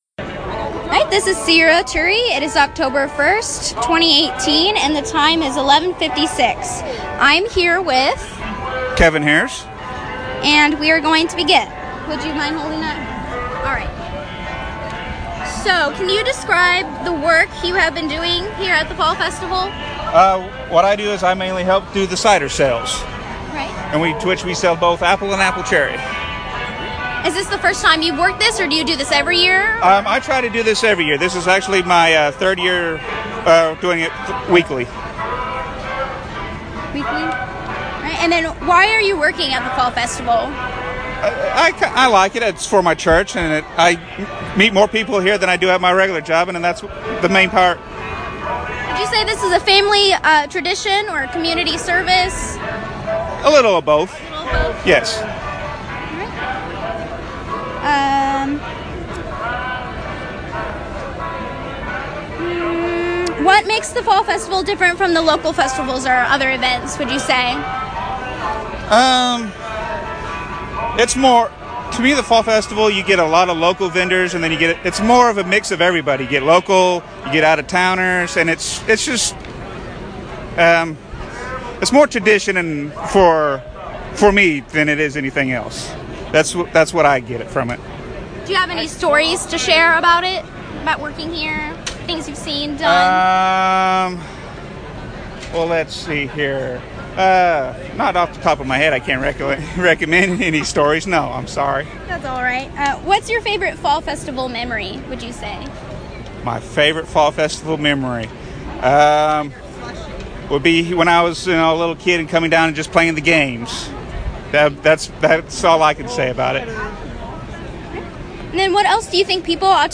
Oral history interview
University Archives & Special Collections > Oral History Collection